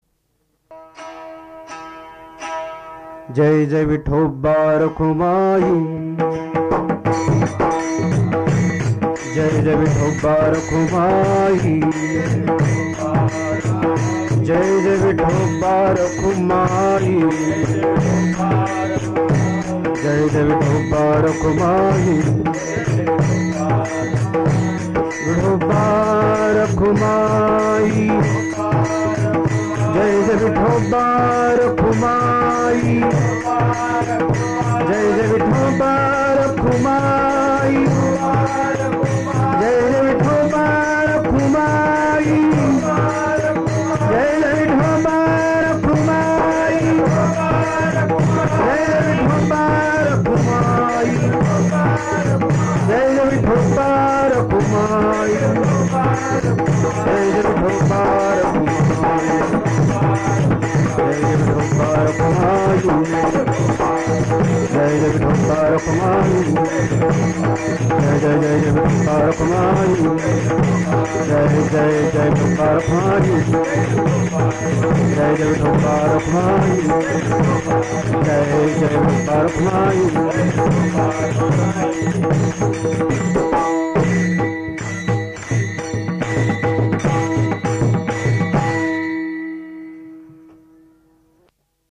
भजन - अभंग श्रवण
पखवाज